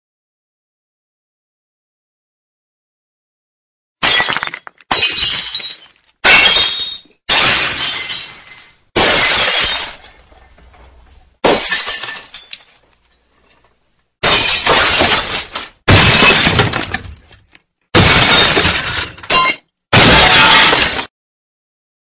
VIDRIO ROMPIEND BROKEN GLASS SOUND EFFECT
EFECTO DE SONIDO DE AMBIENTE de VIDRIO ROMPIEND BROKEN GLASS SOUND EFFECT
Vidrio_Rompiend_-_broken_glass_sound_effect.mp3